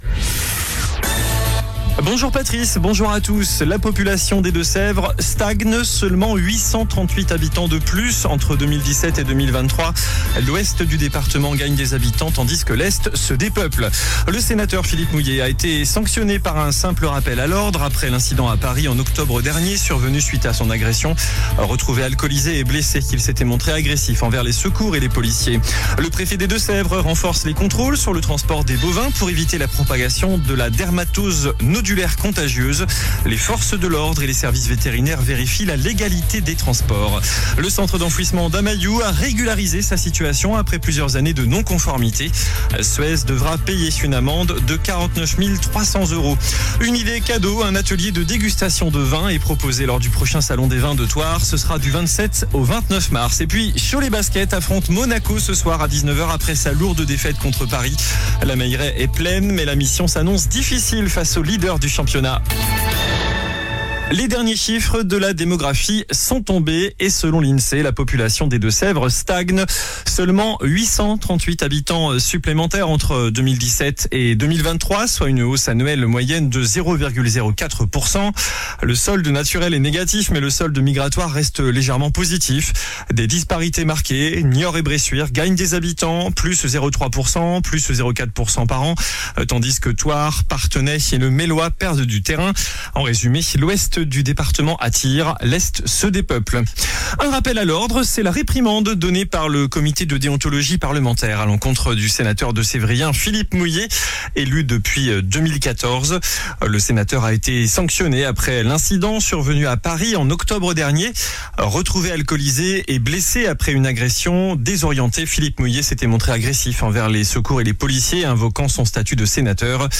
JOURNAL DU MARDI 23 DECEMBRE ( MIDI )